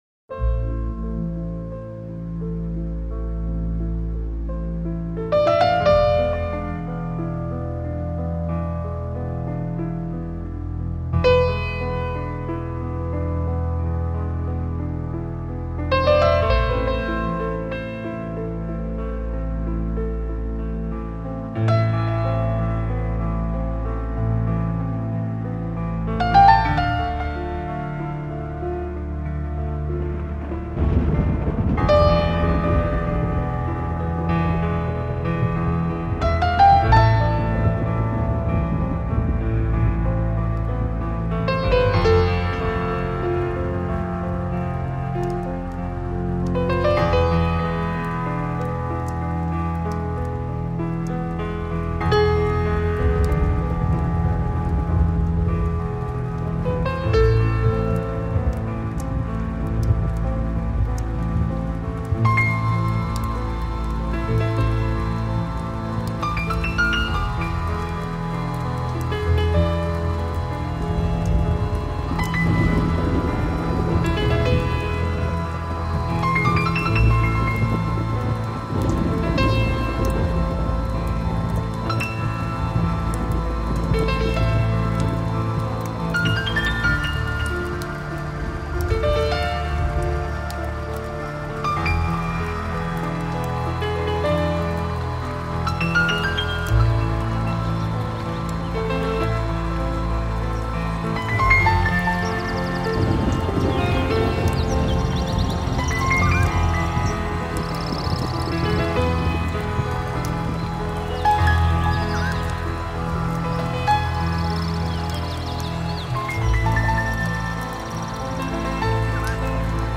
Люблю эту мелодию со звуками грозы....